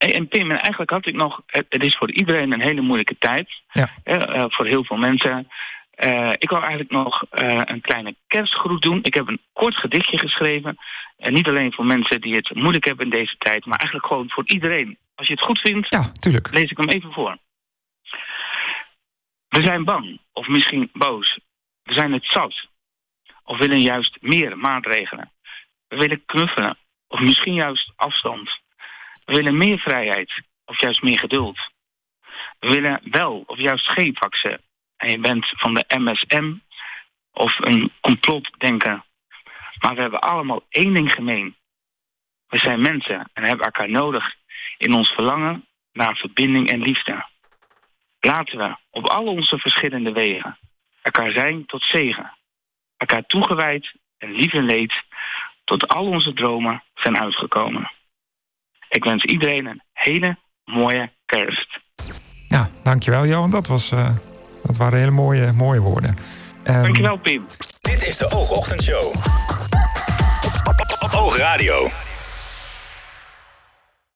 Luister het fragment uit de OOG Ochtendshow hier terug: